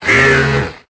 Cri de Crapustule dans Pokémon Épée et Bouclier.